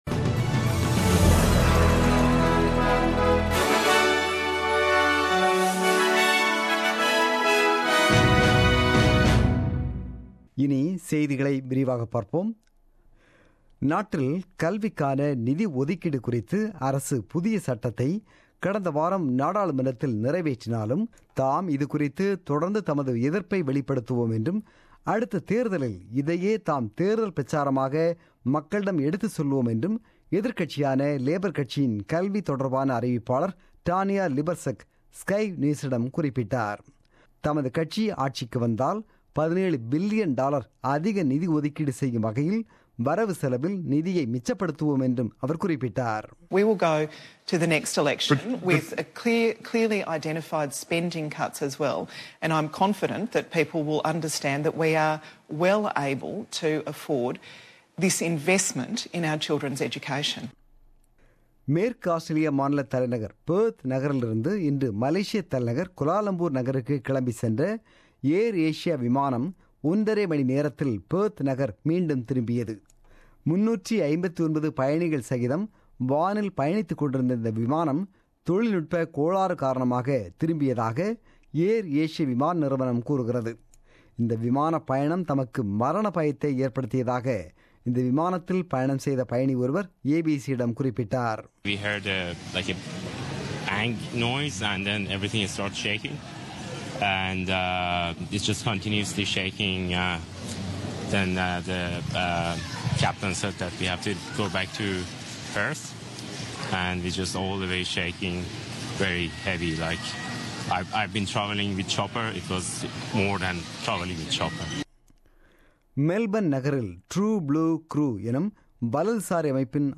The news bulletin broadcasted on 25 June 2017 at 8pm.